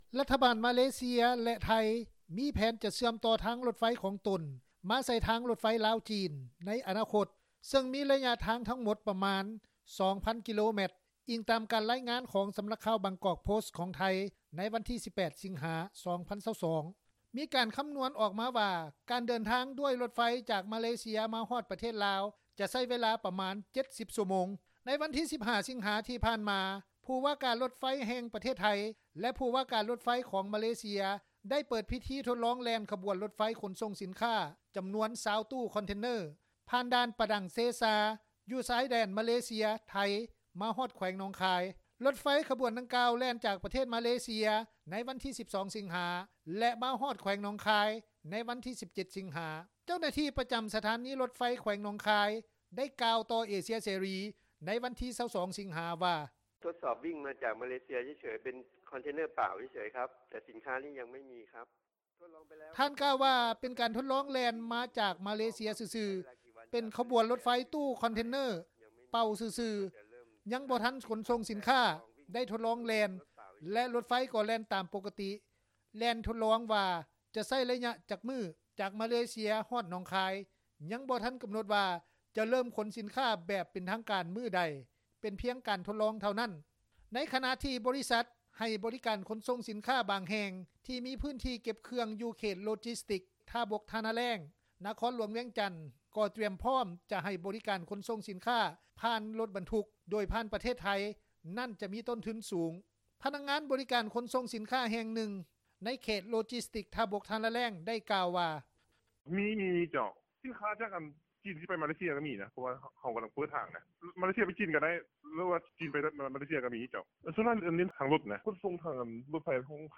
ພະນັກງານບໍຣິການ ຂົນສົ່ງສິນຄ້າແຫ່ງນຶ່ງ ໃນເຂດໂລຈິສຕິກສ໌ ທ່າບົກ-ທ່ານາແລ້ງ ໄດ້ກ່າວວ່າ:
ຜູ້ປະກອບການຂົນສົ່ງສິນຄ້າ ນາງນຶ່ງ ໄດ້ກ່າວວ່າ: